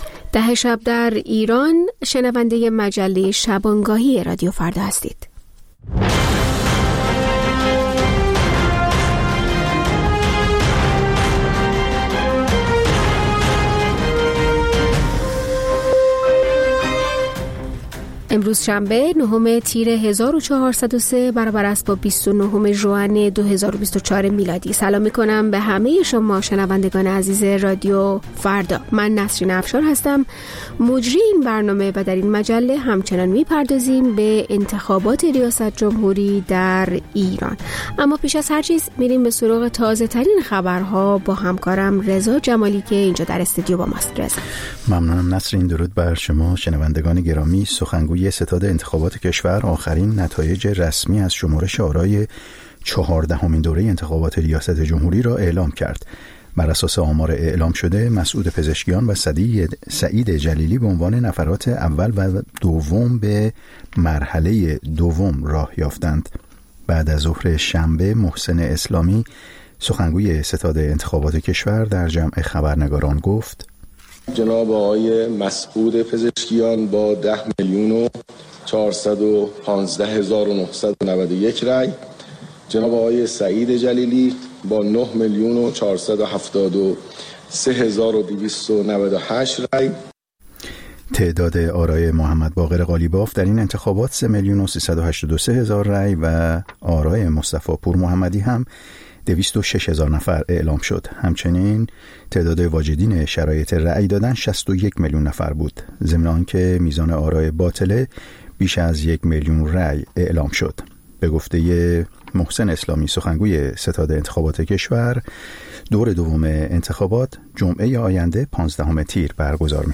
نیم ساعت با تازه‌ترین خبرها، گزارش‌های دست اول در باره آخرین تحولات جهان و ایران از گزارشگران رادیو فردا در چهارگوشه جهان، گفت‌وگوهای اختصاصی با چهره‌های خبرساز و کارشناسان